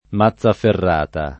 [ ma ZZ aferr # ta ]